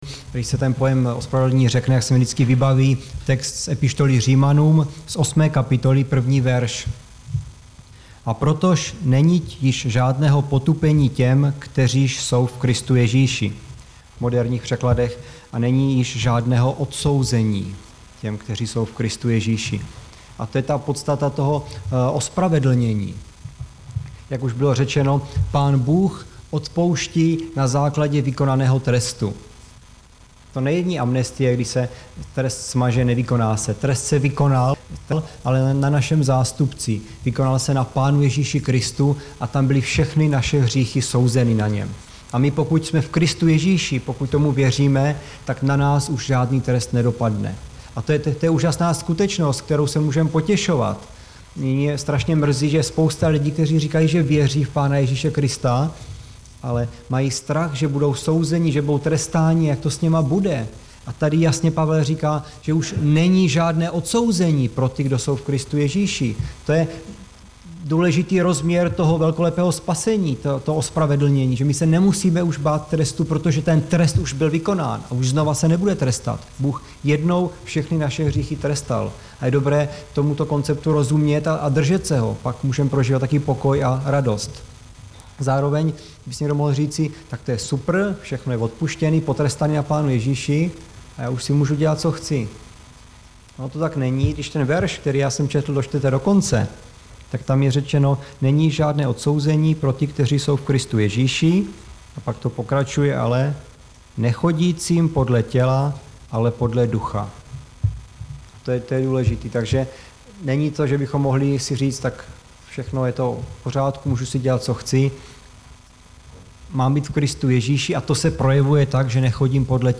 Záznamy z bohoslužeb